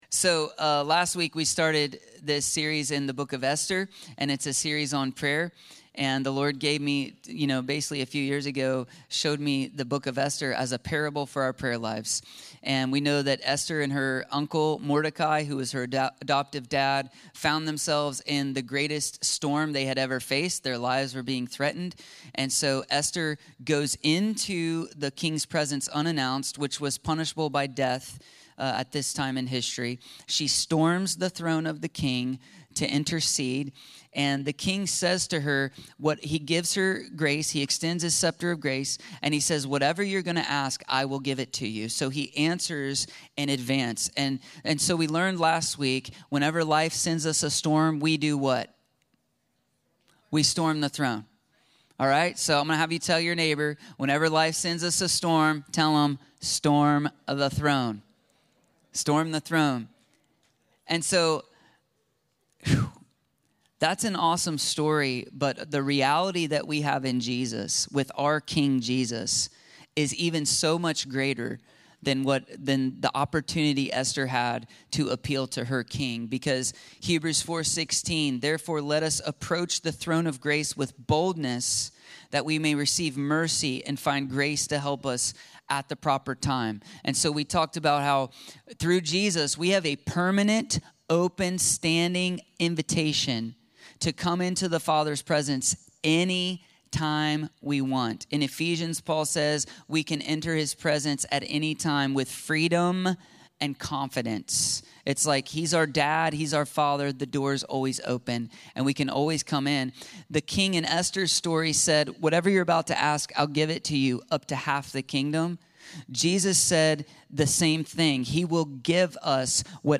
The Power of Persistent Prayer - Storm The Throne ~ Free People Church: AUDIO Sermons Podcast